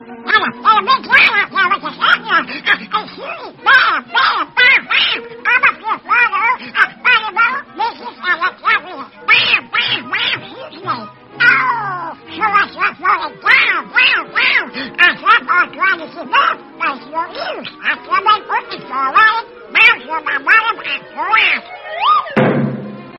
Suara Meme Bebek Ngomel Ke Jerry
Kategori: Efek suara
Download untuk merasakan sensasi lucu suara bebek ngamuk khas meme ini!
suara-meme-bebek-ngomel-ke-jerry-id-www_tiengdong_com.mp3